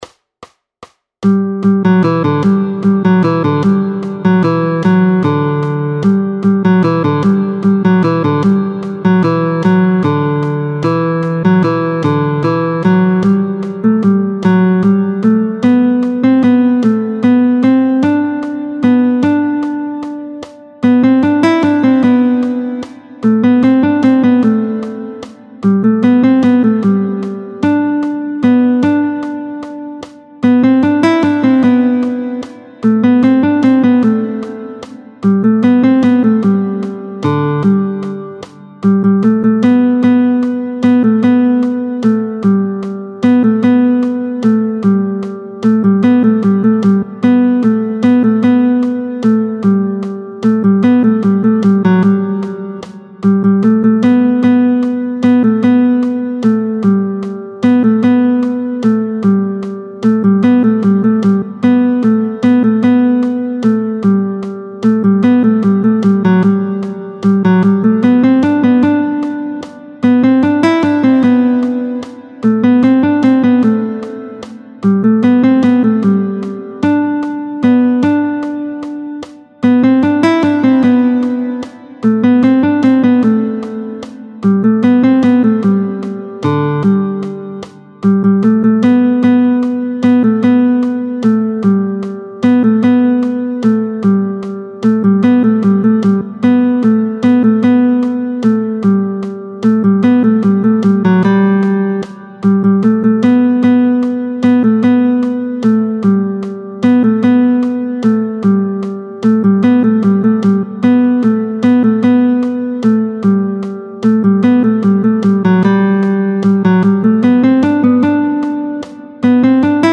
√ برای ساز گیتار | سطح آسان
همراه 3 فایل صوتی برای تمرین هنرجویان